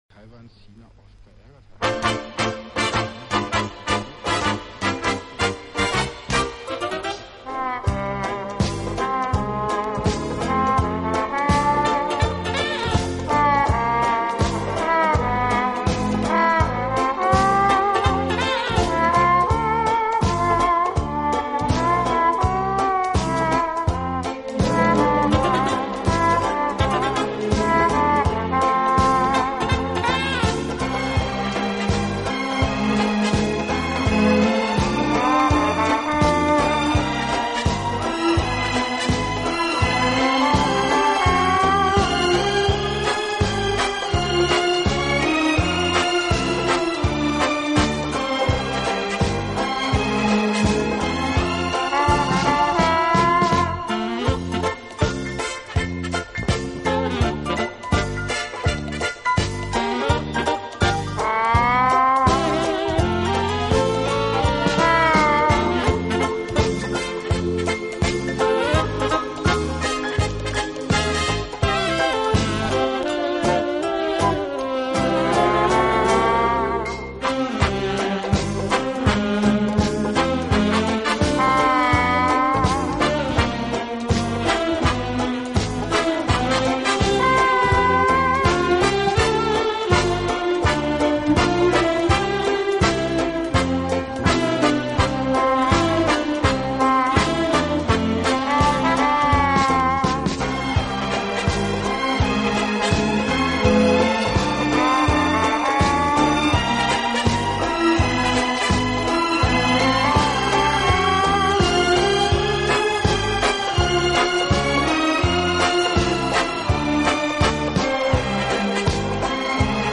方式，尤其是人声唱颂的背景部分，似乎是屡试不爽的良药。
有动感，更有层次感；既有激情，更有浪漫。